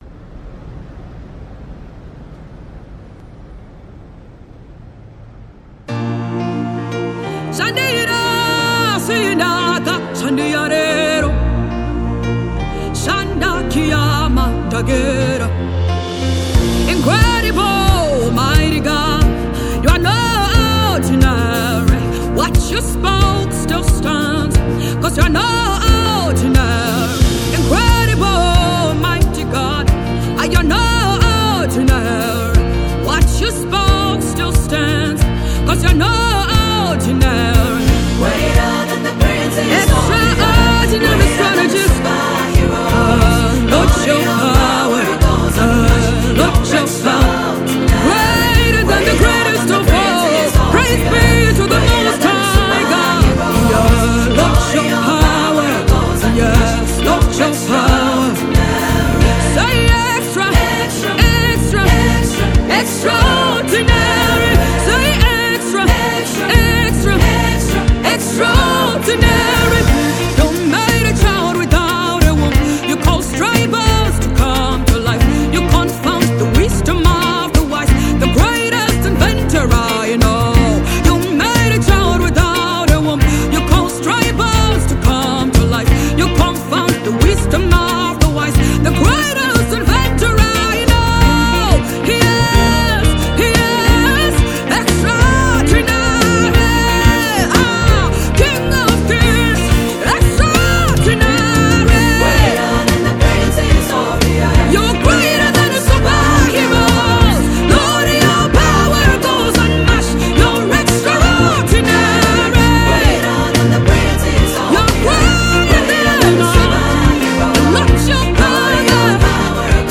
uplifting melody